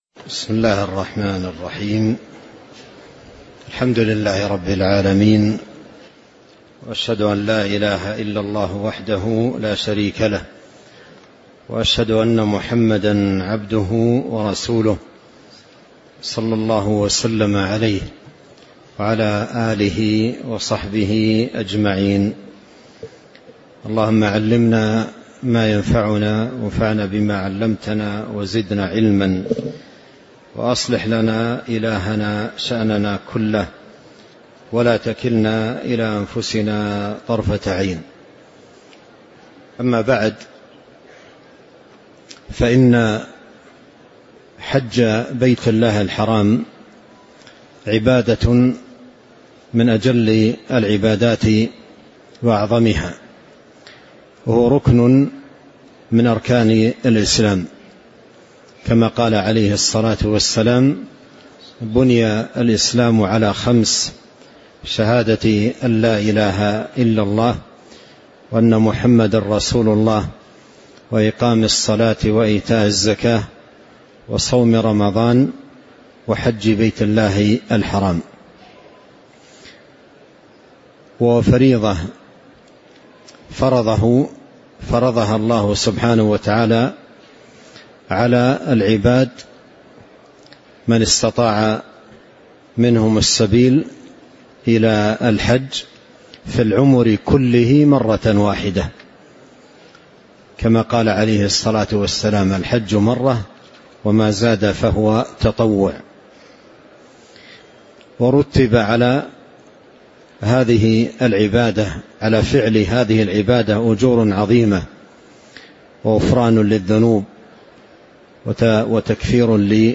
تاريخ النشر ٢٠ ذو القعدة ١٤٤٦ المكان: المسجد النبوي الشيخ: فضيلة الشيخ عبدالرزاق بن عبدالمحسن العباد فضيلة الشيخ عبدالرزاق بن عبدالمحسن العباد 1 قوله باب فرض الحج